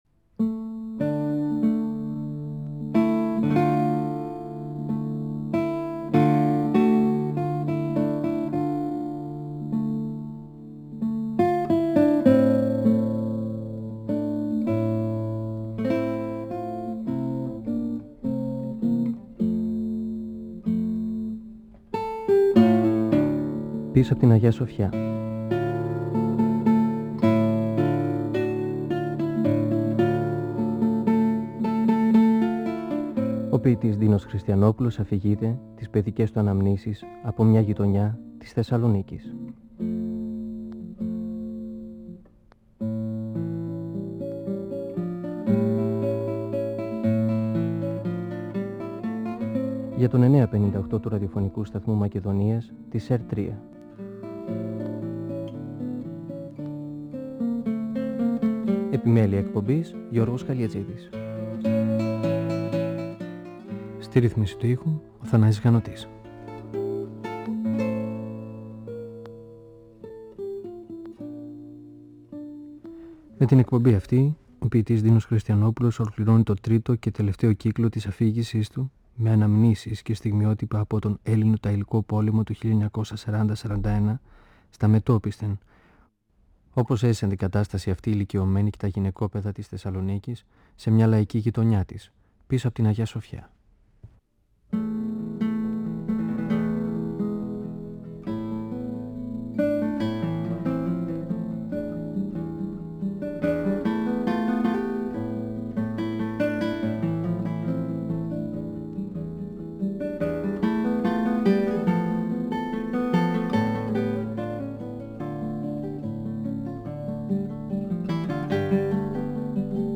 (Εκπομπή 14η) Ο ποιητής Ντίνος Χριστιανόπουλος (1931-2020), στην τελευταία εκπομπή της σειράς αυτής, μιλά για τις αναμνήσεις του από το μια παλιά γειτονιά της Θεσσαλονίκης, πίσω απ’ την Αγια-Σοφιά και για την αναγκαστική μετακόμιση σ’ ένα δωμάτιο στην οδό Φλωρίνης, μετά τον βομβαρδισμό του σπιτιού τους. Περιγράφει την είσοδο των Γερμανών στη Θεσσαλονίκη, στις 9 Απριλίου 1941, και την υποδοχή τους στην Εγνατία.